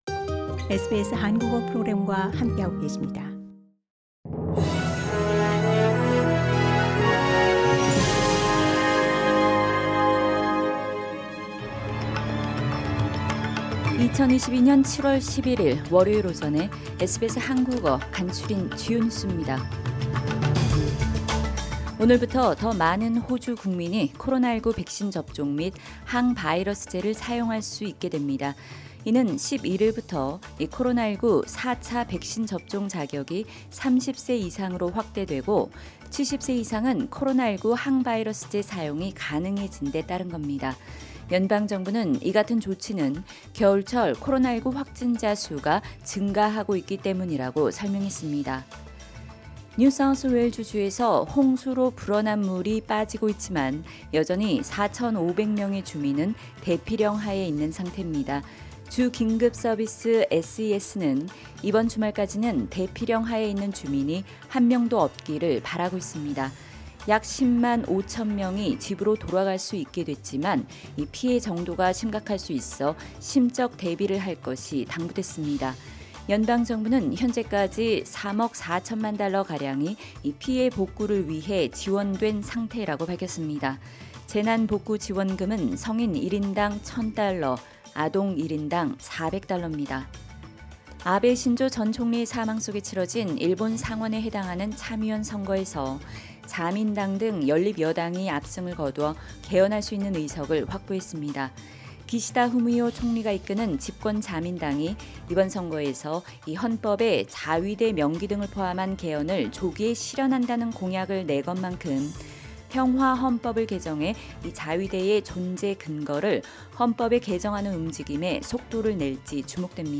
SBS 한국어 아침 뉴스: 2022년 7월 11일 월요일